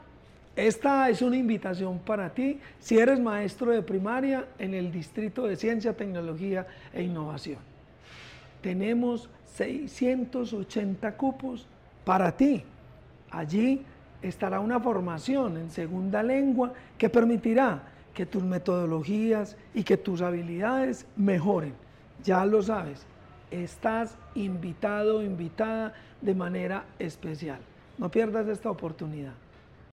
Declaraciones subsecretario de la Prestación del Servicio Educativo, Jorge Iván Ríos Rivera
Declaraciones-subsecretario-de-la-Prestacion-del-Servicio-Educativo-Jorge-Ivan-Rios-Rivera.mp3